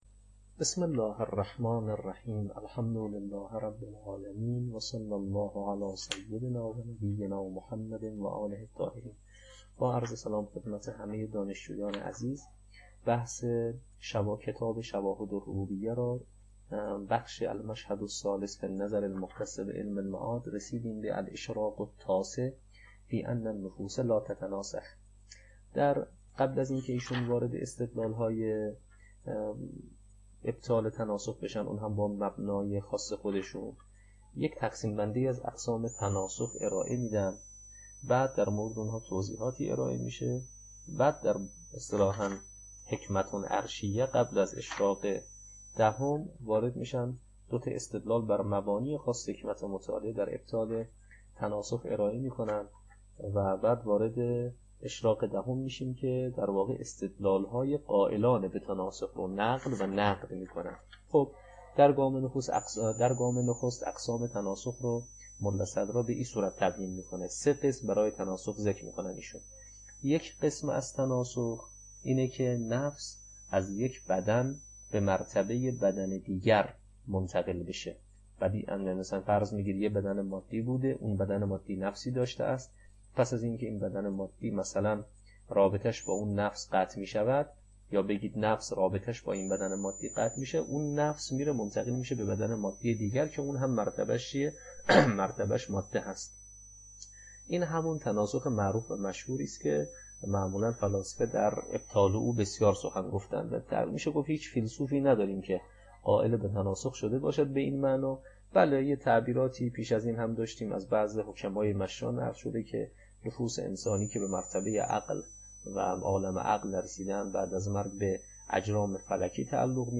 تدریس کلام تطبیقی